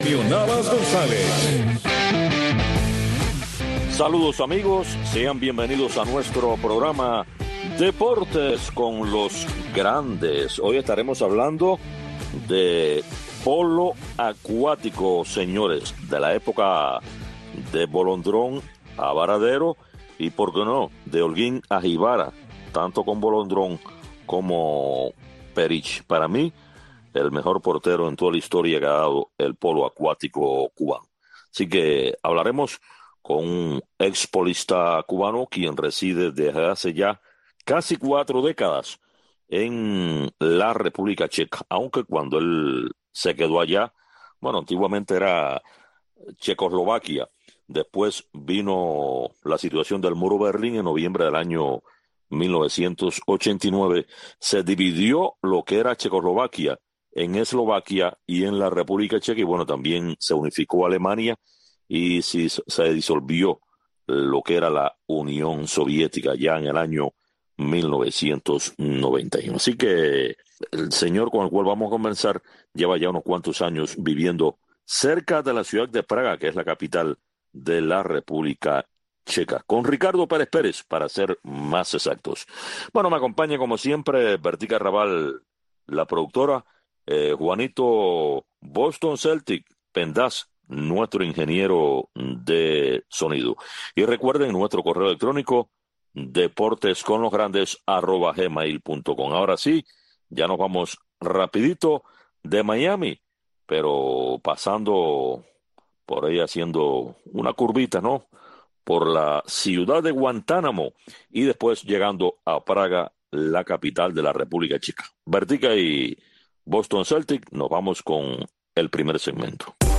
Deportes con los Grandes. Un programa de Radio Martí, especializado en entrevistas, comentarios, análisis de los Grandes del deporte.